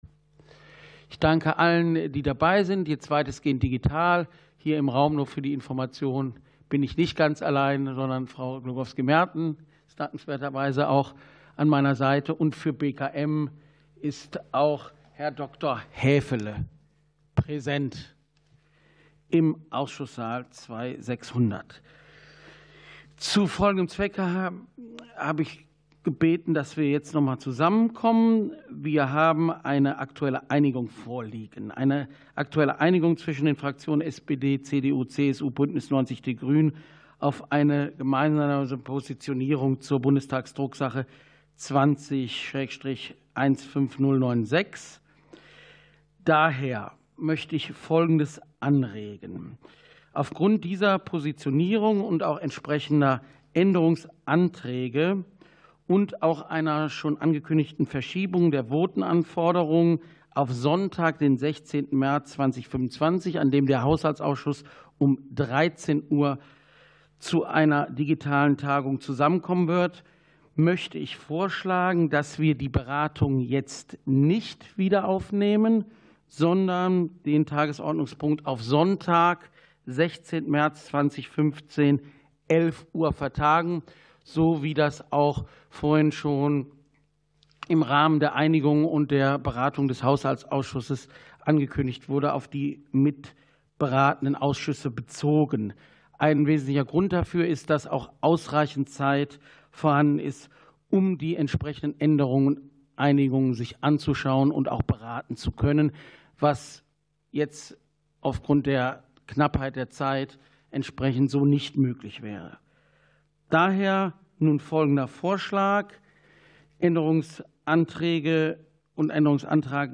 Ausschusssitzungen - Audio Podcasts